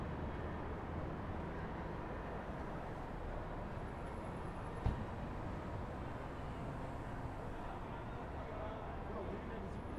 Environmental
Streetsounds